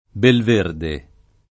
Belverde [ belv % rde ]